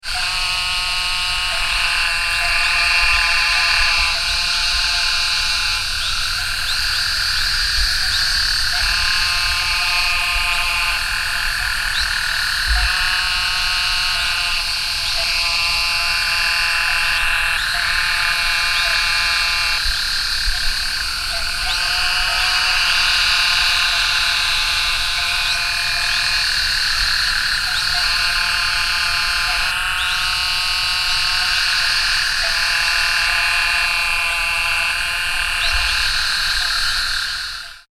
Sheep Frog - Hypopachus variolosus
The sound of the Sheep Frog is a clear bleating (like a sheep) of about 2 seconds duration, repeated at approximately 15 second intervals.
sound  This is a 38 second recording of the advertisement calls of a chorssus of Sheep Frogs. Gulf Coast Toads, Great Plains Narrow-mouthed Toads, and many different insects are heard in the background. The recording was made at night in early July at the edge of a flooded ditch in Jim Wells County, Texas.
sheepfroglong2.mp3